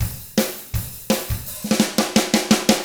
164ROCK F1-R.wav